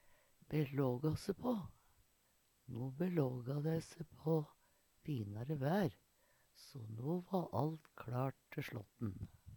DIALEKTORD PÅ NORMERT NORSK belågå se på førbu seg til Eksempel på bruk No belågå dei se på finare vær so alt va kLart te slåtten.